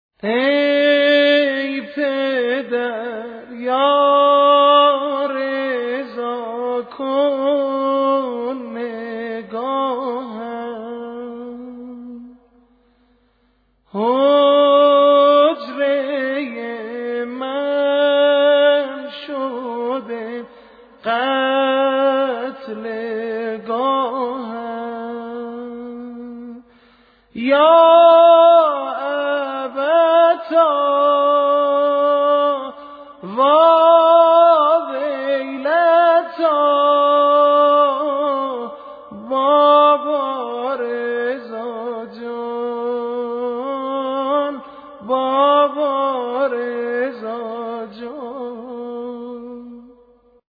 نوحه با سبک برای شهادت جوادالائمه (ع) - (ای پدر یا رضا کن نگاهم)